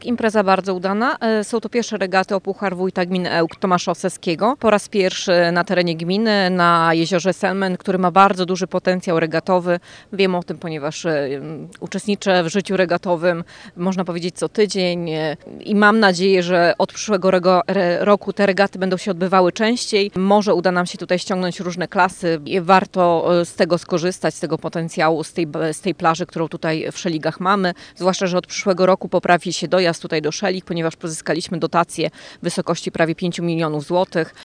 Jezioro Selmęt Wielki ma ogromny potencjał regatowy – mówi zastępca wójta gminy Ełk.